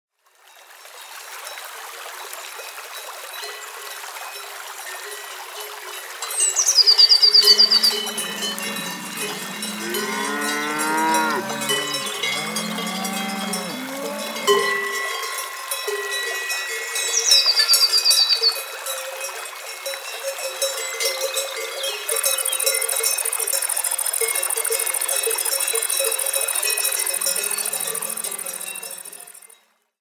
Erleben sie Alpen-Atmosphäre mit Kühen, Ziegen, Vogelzwitschern und mehr.
• alpine Klänge
Die natürliche Klangkulisse mit Duo-Speaker-System entspannt intuitiv
• Duo-Speaker, einer spielt eine authentische Alpen-Atmosphäre, der andere ergänzt alpine Akzente
Kuhglocken- und Alphornklänge, Waldklang